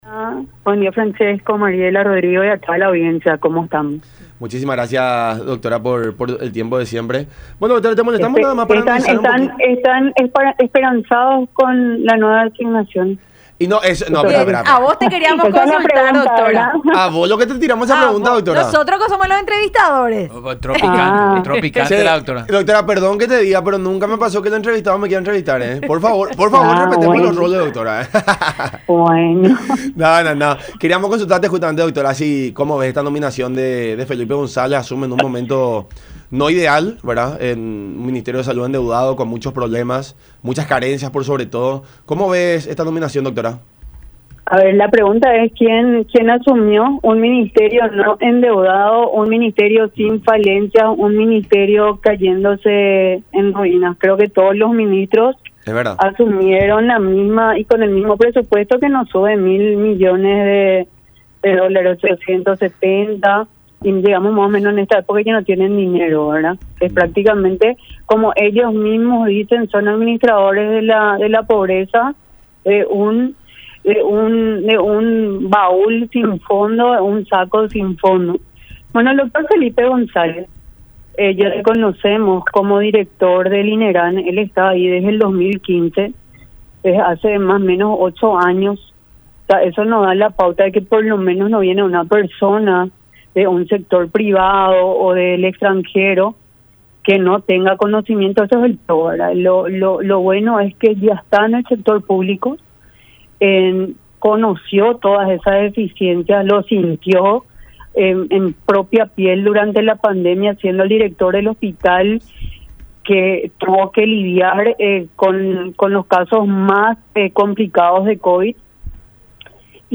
en diálogo con La Unión Hace La Fuerza a través de Unión TV y radio La Unión